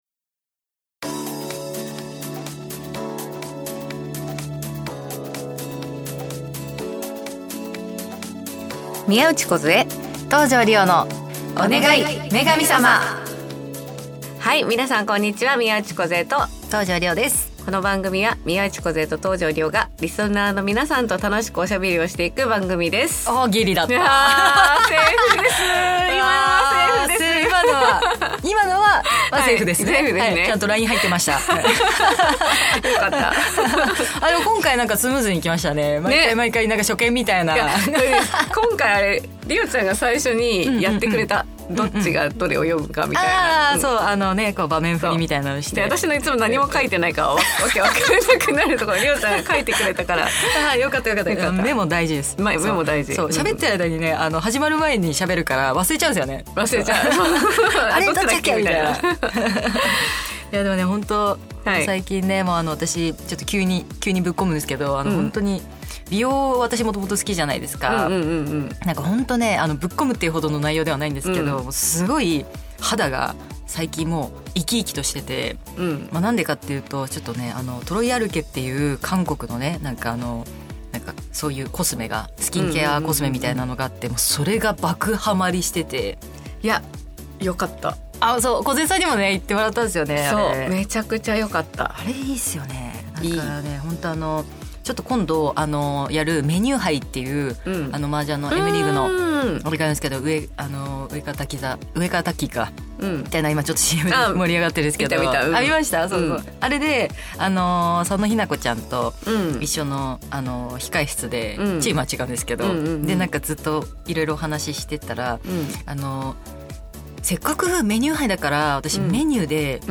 久しぶりのスタジオ収録です♪次回の公開収録はまさかの沖縄か北海道・・！？